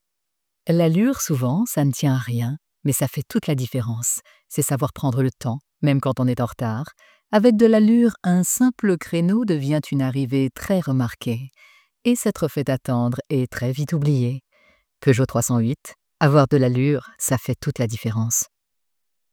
Publicité - Voix dynamique
- Mezzo-soprano